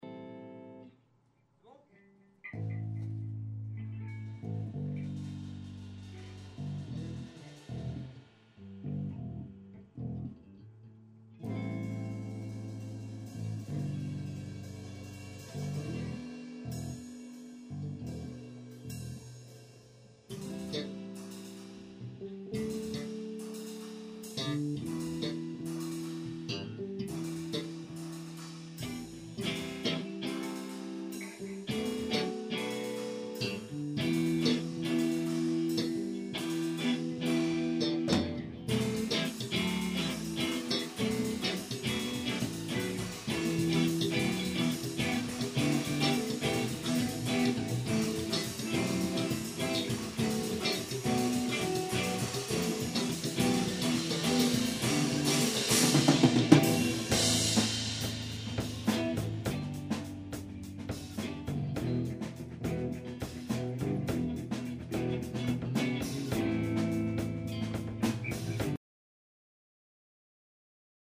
Prises de sons live ce qui implique parfois des petits défauts.
@ Répétition du 14 décembre 2008 @
Lien pour télécharger la partie 04 (Impro)